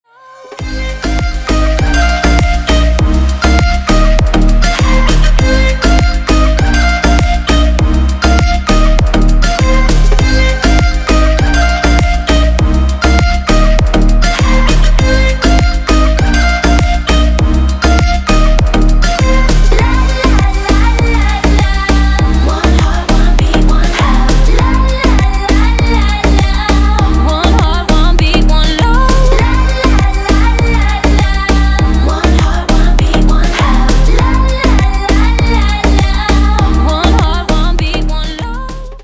• Качество: 320, Stereo
женский вокал
deep house
dance
Electronic
tropical house
забавный голос
теплые
Стиль: deep, tropical house